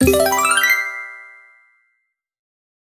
Base game sfx done
Nice Reward 1.wav